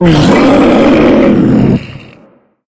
sounds / monsters / psysucker / hit_2.ogg
hit_2.ogg